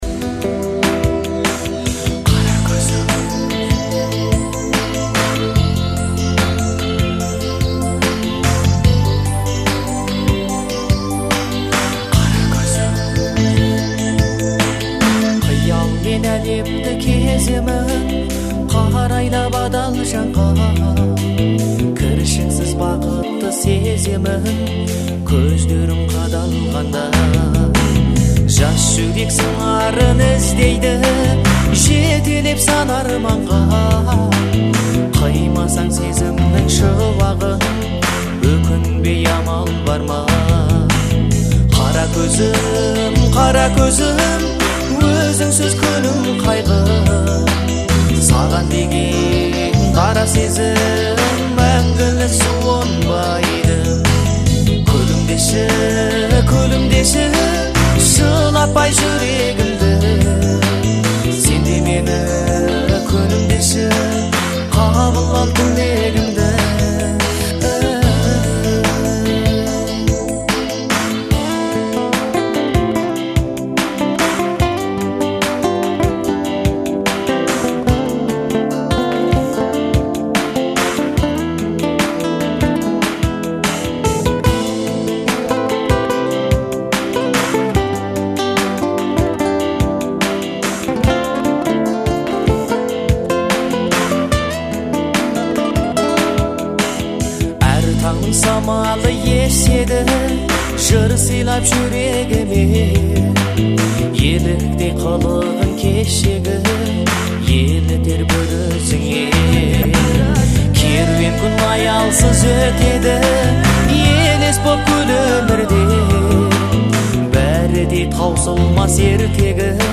это трогательная казахская песня в жанре поп-фолк